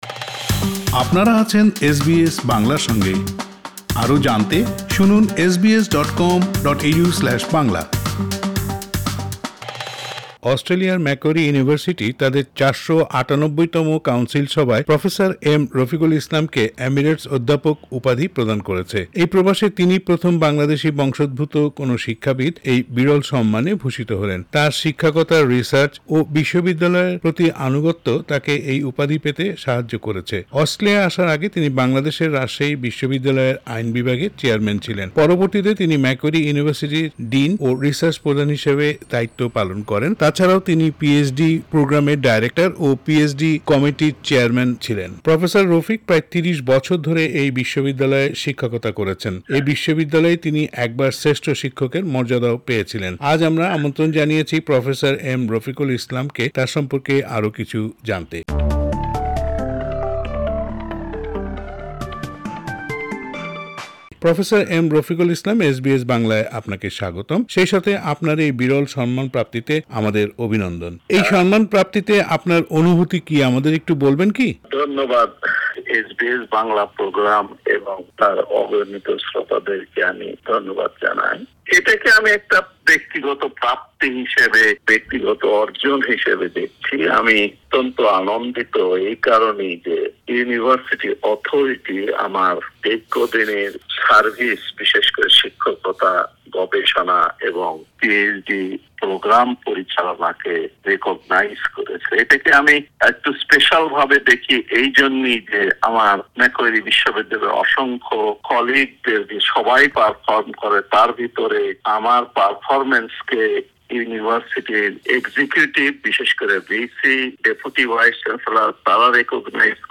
এসবিএস বাংলার সাথে দেয়া এক সাক্ষাৎকারে তিনি তার বর্ণাঢ্য আলোকিত জীবনের গল্প বলেন ।